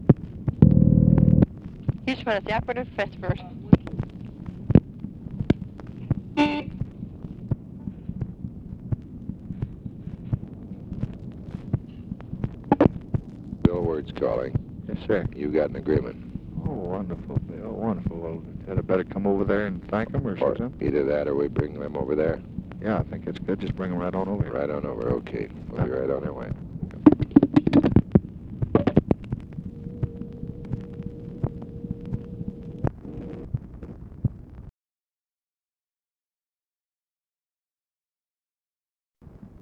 Conversation with WILLARD WIRTZ, September 3, 1965
Secret White House Tapes